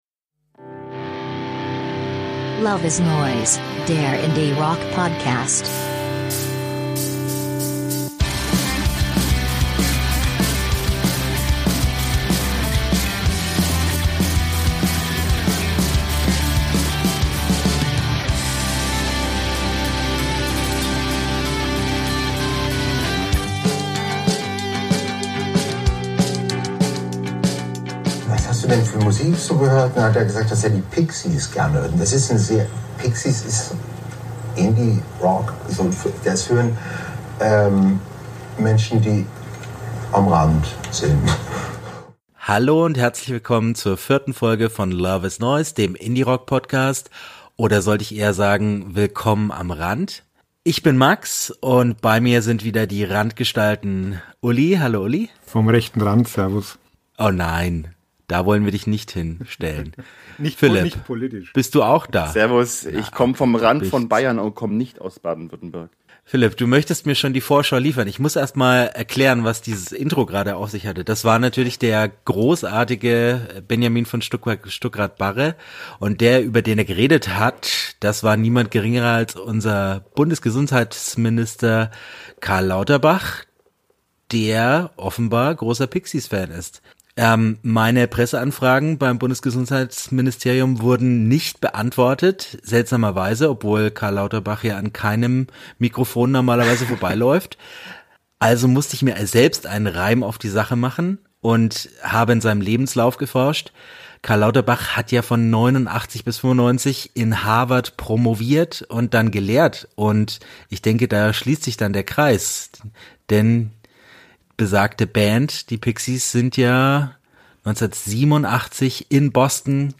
Stets bierselig, kontrovers und gut gelaunt.